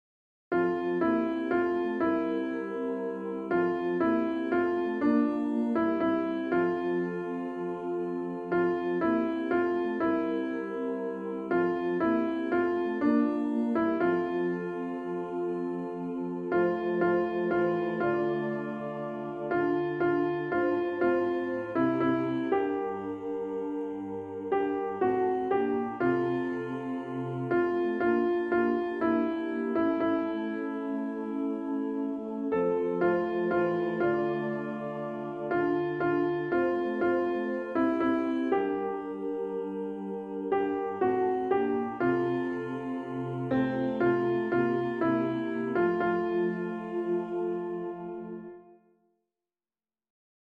Alto emphasized
A Song of Peace-Alto.mp3